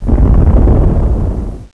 rumble.wav